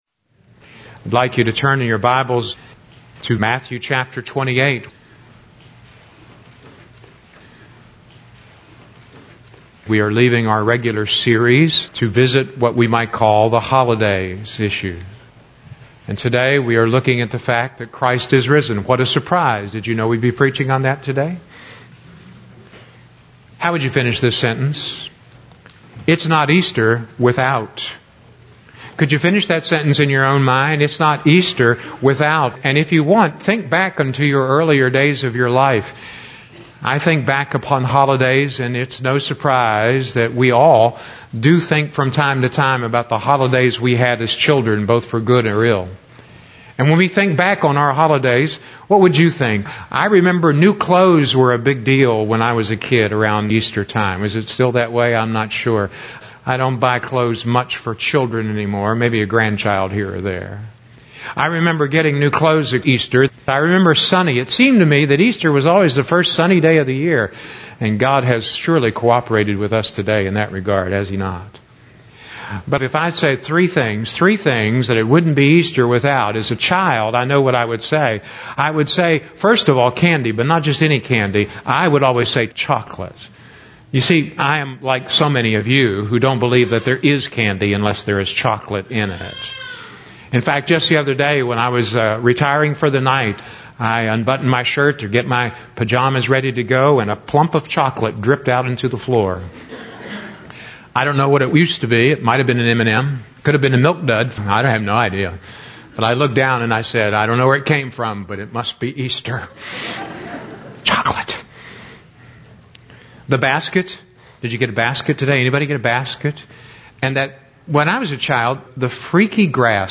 Matthew 28:1-10 Service Type: Sunday Morning Sermon Notes